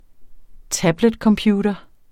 Udtale [ ˈtablεd- ]